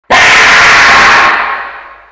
Sound Effects
Hl2 Stalker Scream